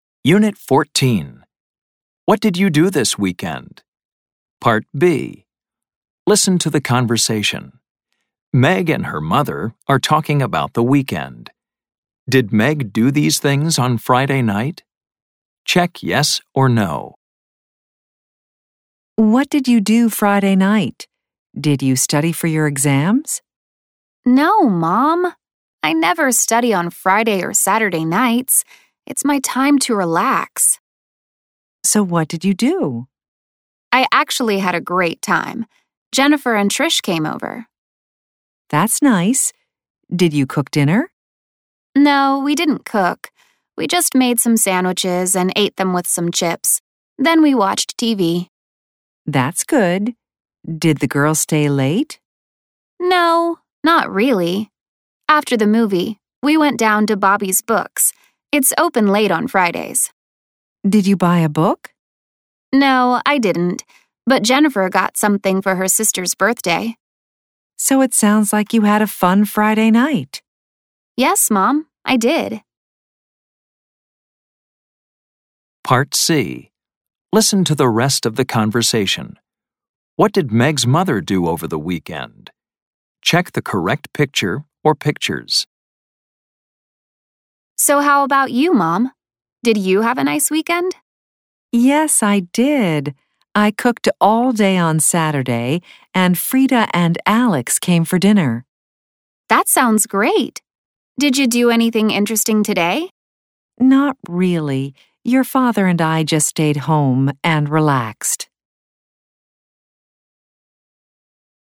American English
This includes Snapshots, Conversations, Grammar Focus, Listening, Pronunciation practice, Word Power, and Reading, all recorded in natural conversational English.